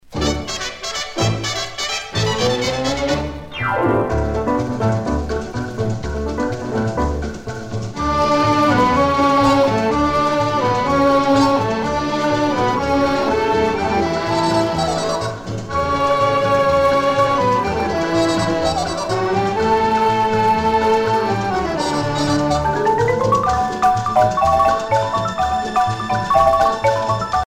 danse : rumba
Pièce musicale éditée